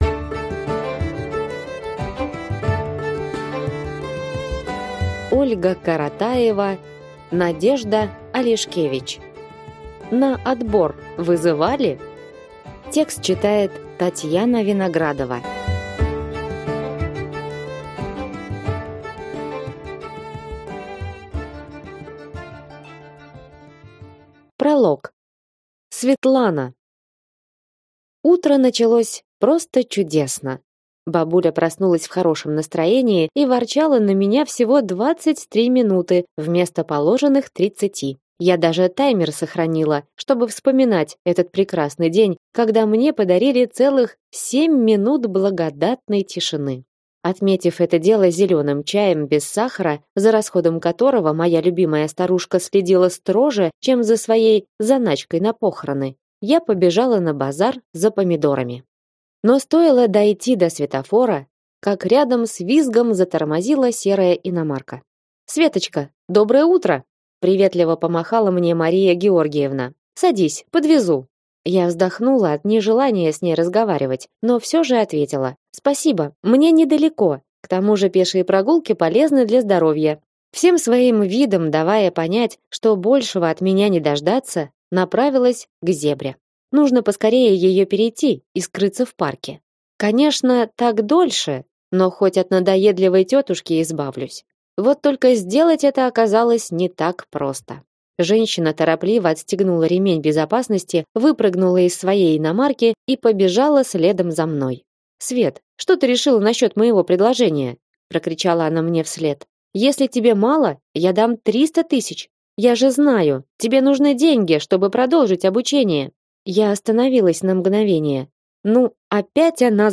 Аудиокнига На отбор вызывали?